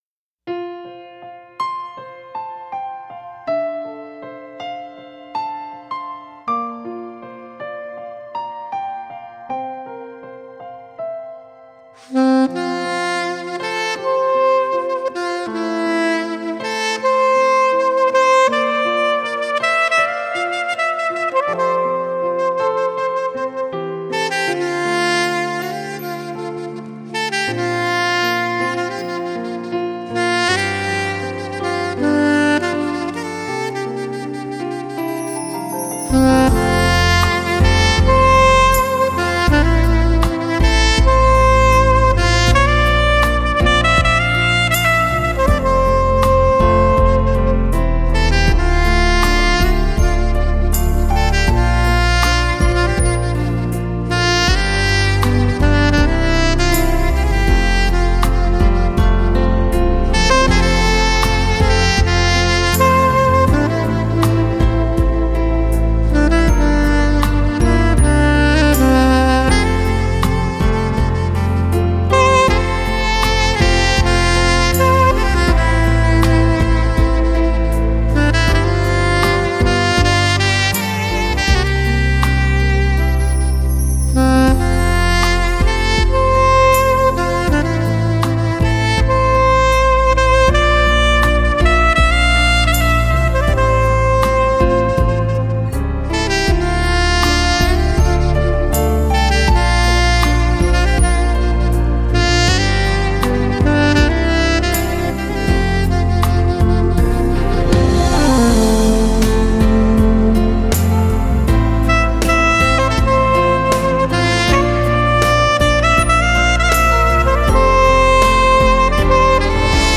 萨克斯与钢琴对话